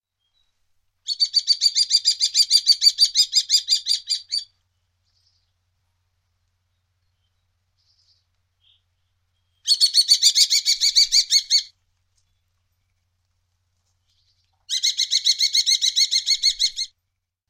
faucon-crecerelle.mp3